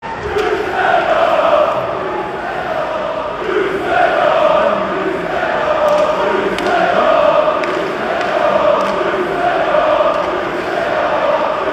Wie bei nahezu jeder Teamsportart dürfen auch beim Eishockey die Fangesänge nicht fehlen.